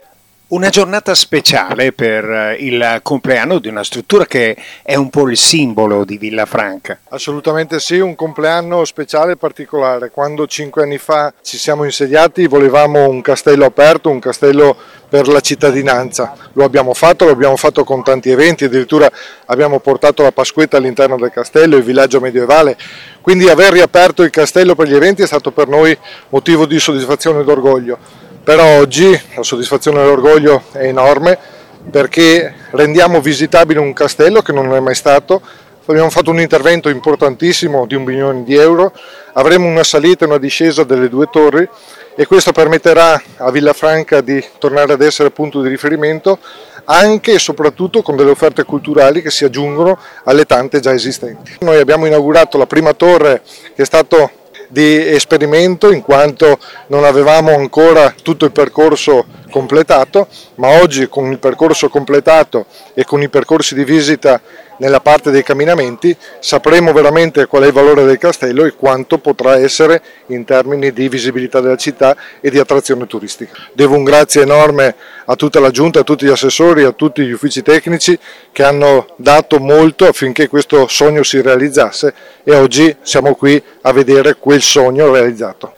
Al nostro microfono il sindaco di Villafranca di Verona, Roberto Dall’Oca:
Sindaco-di-Villafranca-Roberto-DallOca-sul-castello.mp3